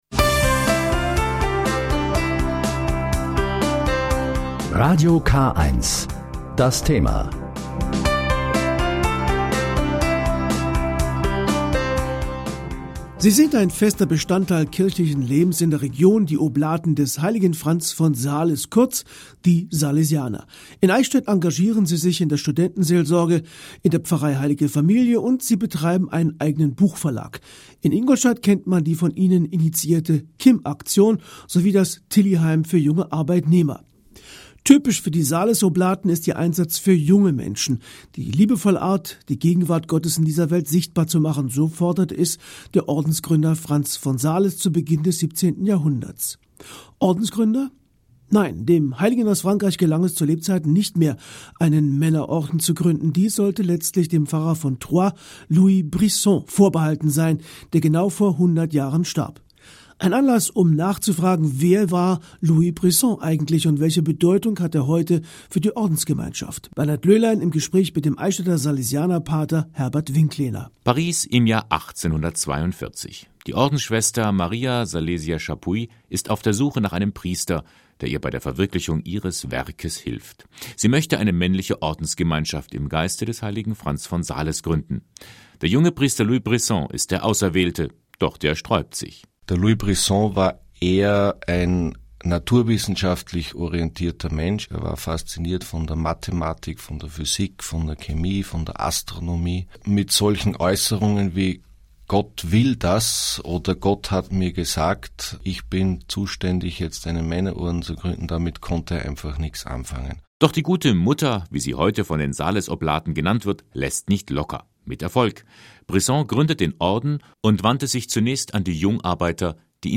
Louis Brisson (Radiosendung K1 Eichstätt - MP3-File 3:41 min)